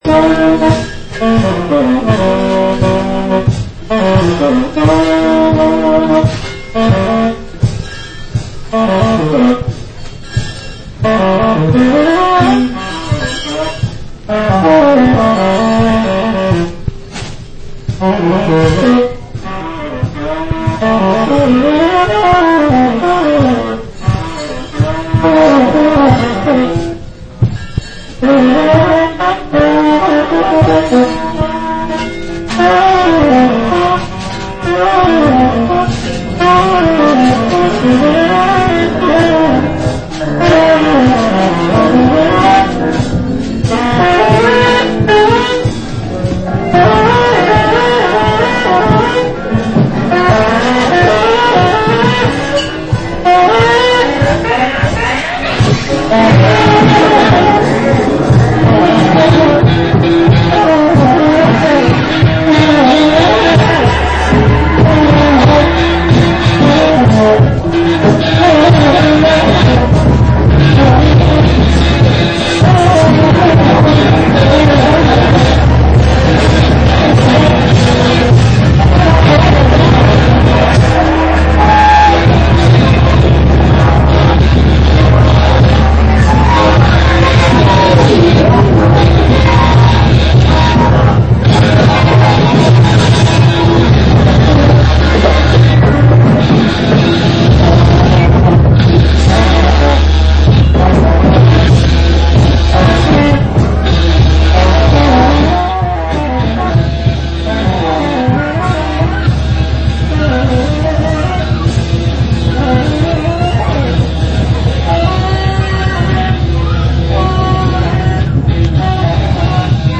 hosts this weekly show live fro...